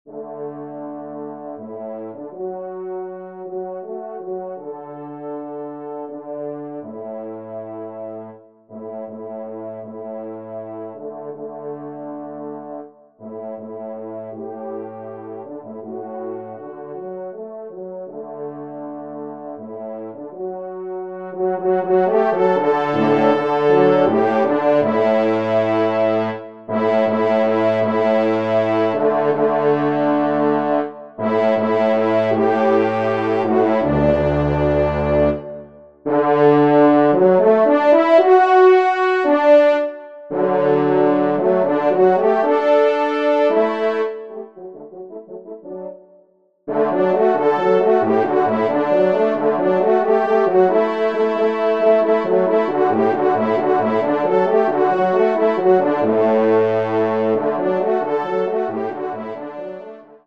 Genre :  Divertissement pour quatre Trompes ou Cors en Ré
Pupitre 3°Trompe